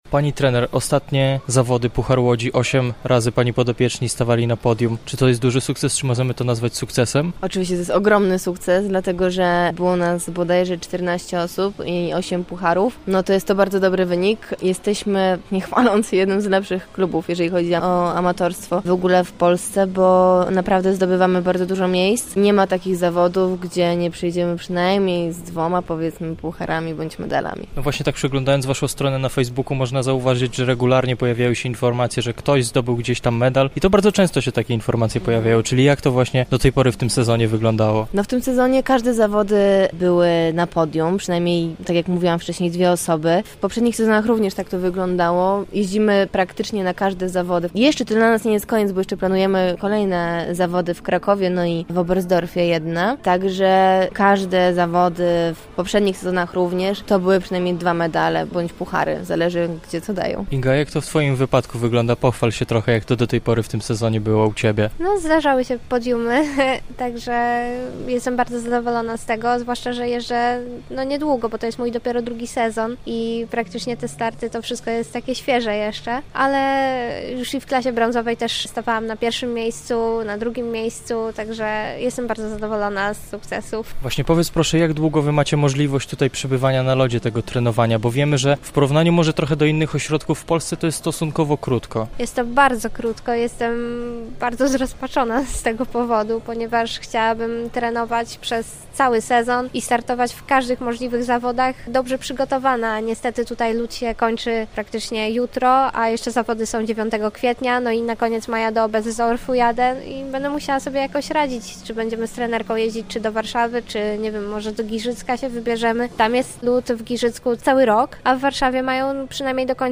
MINIWYWIAD-MKŁ-Libella-Lublin.mp3